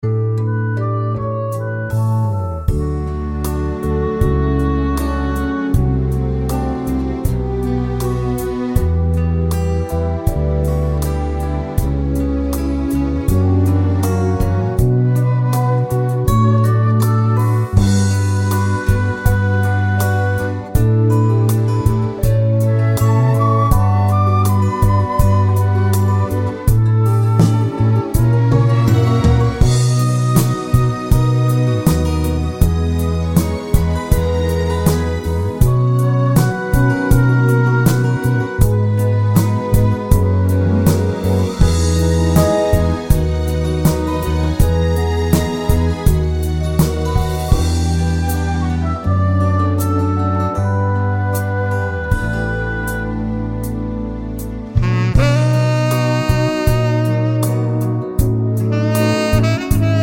no Backing Vocals Pop (1970s) 3:50 Buy £1.50